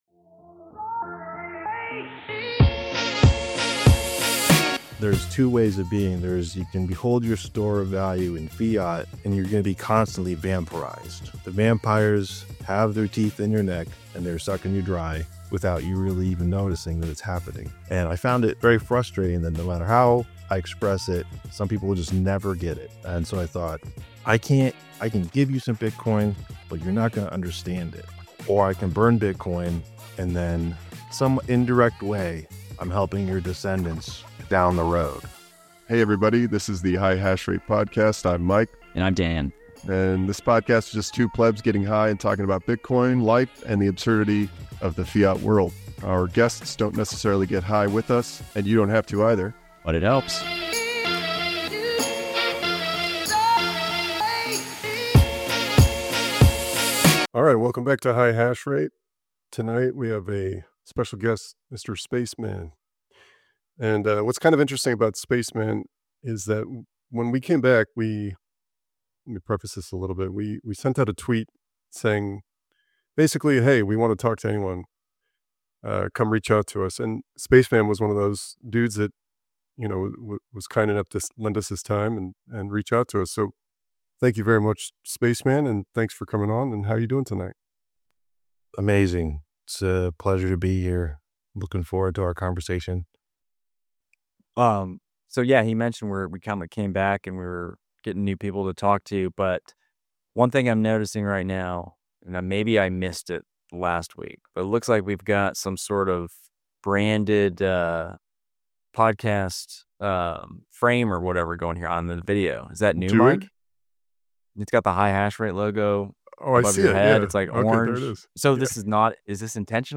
High level conversations with Bitcoiners about Bitcoin and how it changes our perspective of reality.